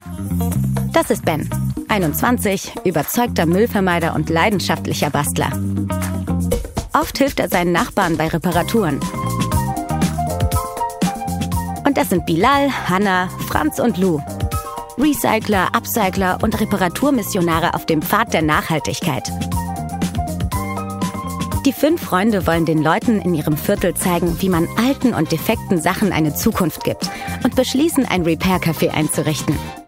Vídeos corporativos
Micrófono: Neumann TLM 103